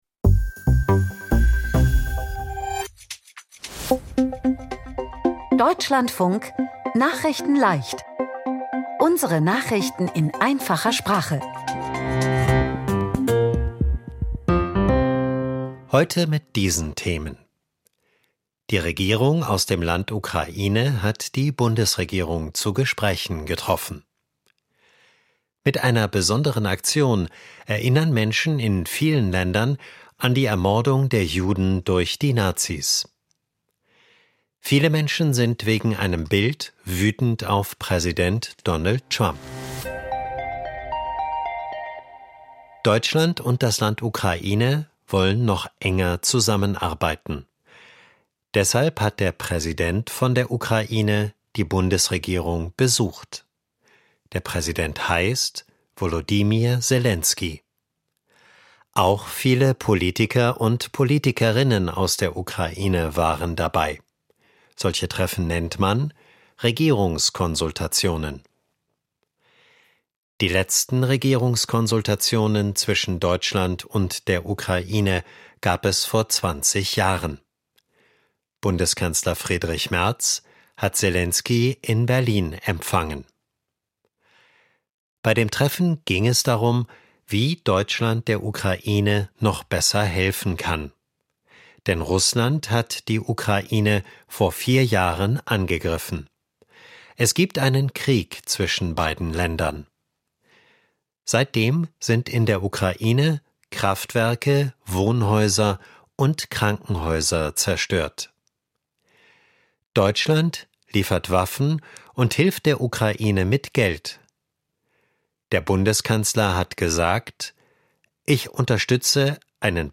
Nachrichten in Einfacher Sprache vom 14.04.2026